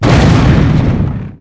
expl2.ogg